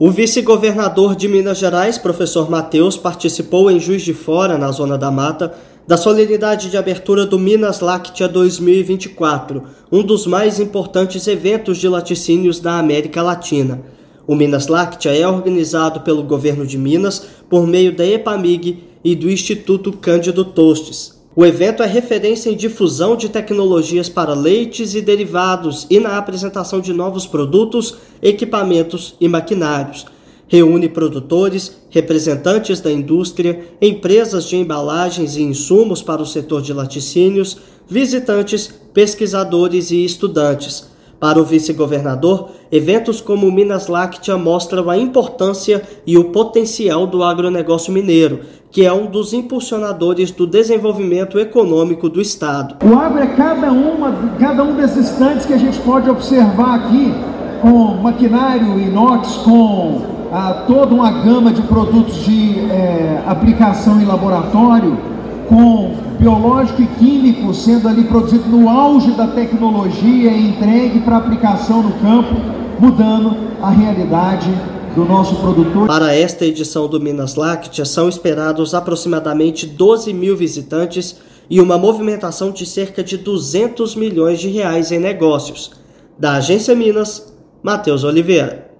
Realizado em Juiz de Fora, um dos mais importantes eventos do setor na América Latina promove o desenvolvimento da produção e apresenta novidades. Ouça matéria de rádio.